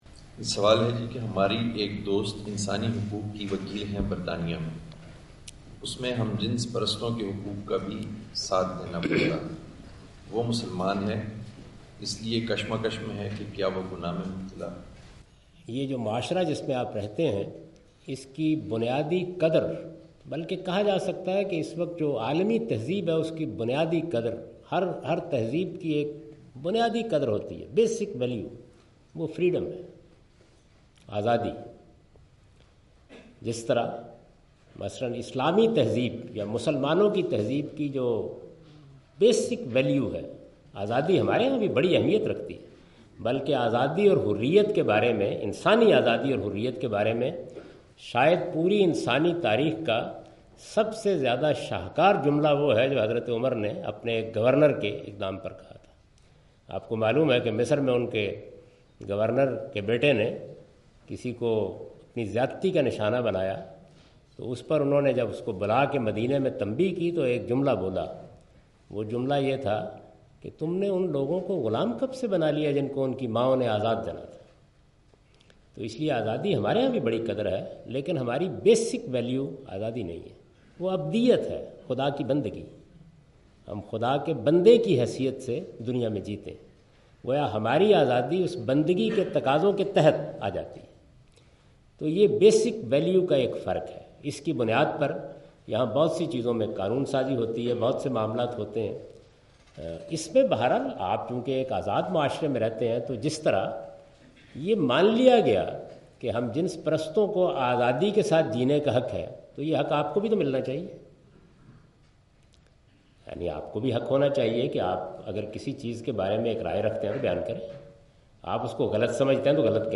Javed Ahmad Ghamidi answers the question "Homosexuality and Ethics" during his Visit of Brunel University London in March 12, 2016.
جاوید احمد صاحب غامدی اپنے دورہ برطانیہ 2016 کےدوران برونل یونیورسٹی لندن میں "ہم جنس پرستی اور اخلاقیات" سے متعلق ایک سوال کا جواب دے رہے ہیں۔